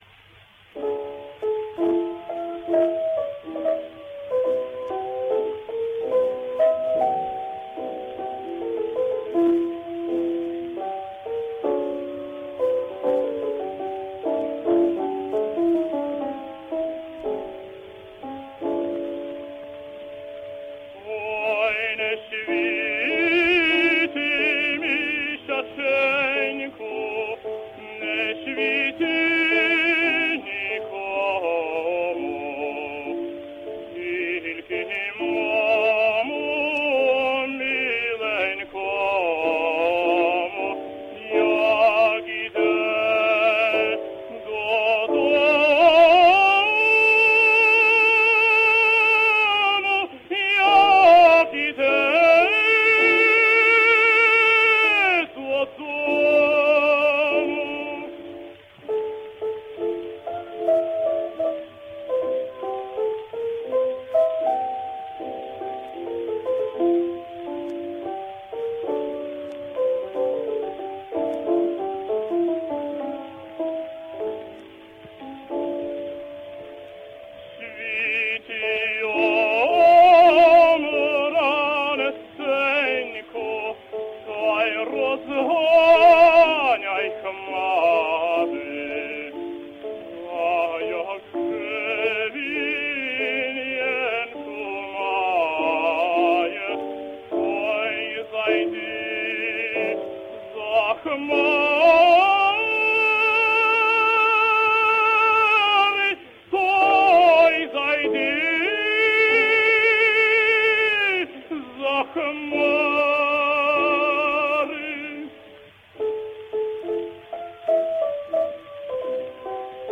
Ukranian Tenor.
Song / ( Lysenko) / 1910 – Modest Menzinsky